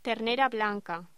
Locución: Ternera blanca